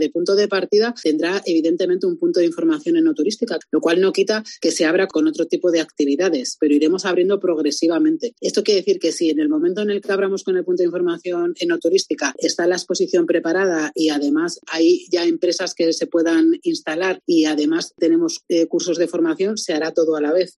Esmeralda Campos, concejala de Turismo de Logroño